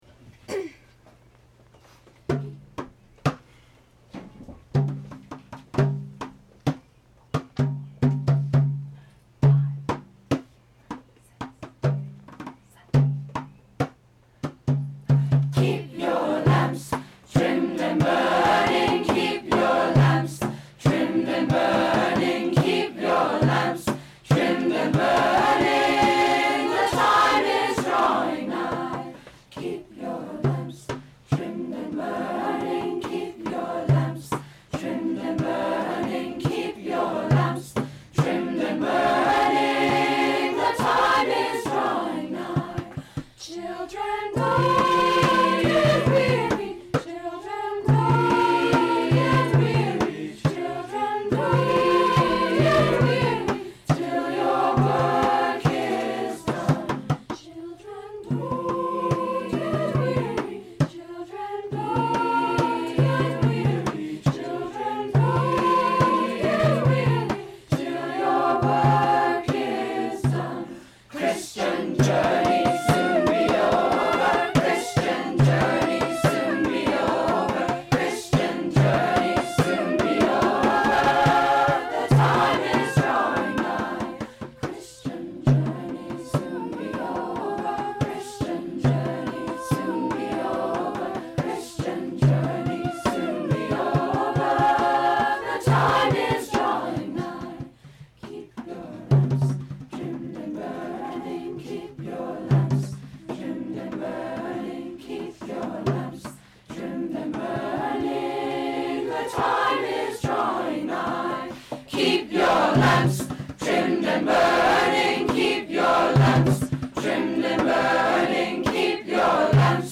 the students
Chamber, Choral & Orchestral Music
2:00 PM on July 20, 2013, "Music with a View"
Chorus
Keep Your Lamps!Trad. Spiritual, arr. Andre Thomas